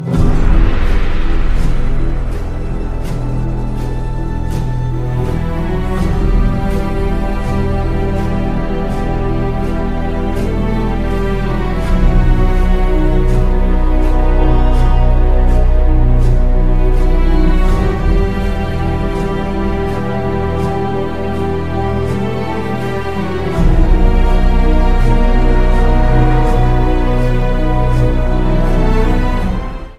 Filmmusik